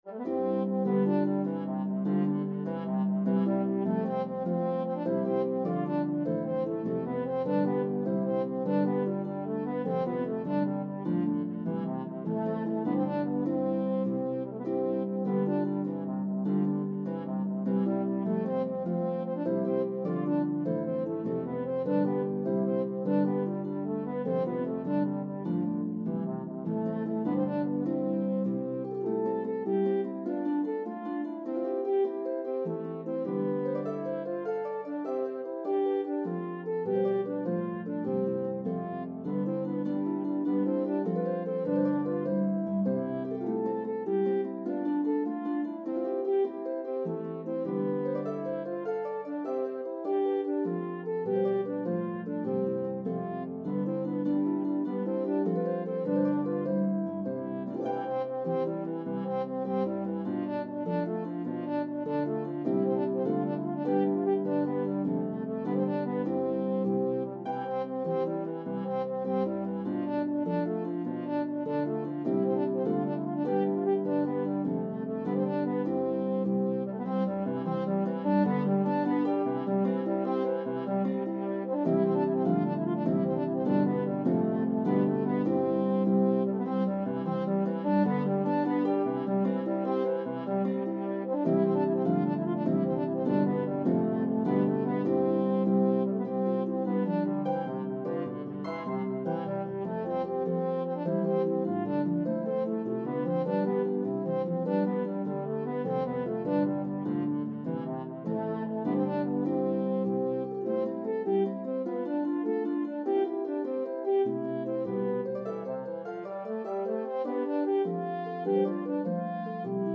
A spirited Irish Jig
The Harp part is playable on either Lever or Pedal Harps.